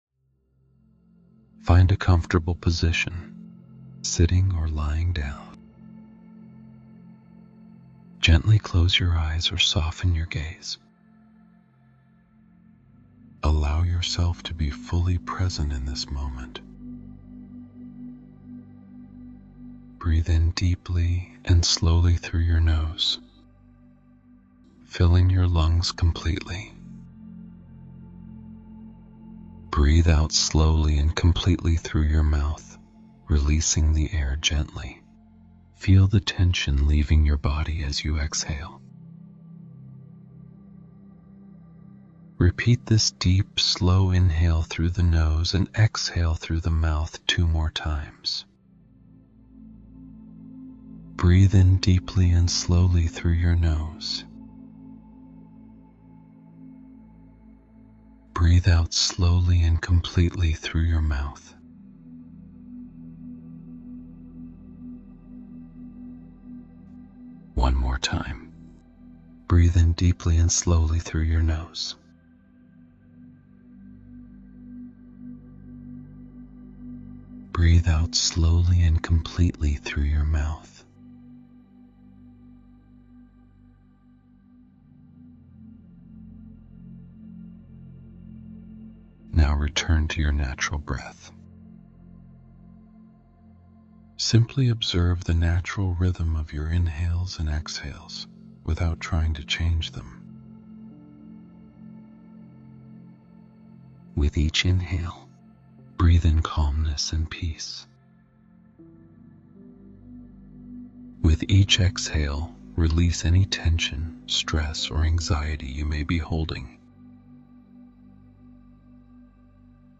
Grounded in Calm: A Meditation for Job Security and Career Uncertainty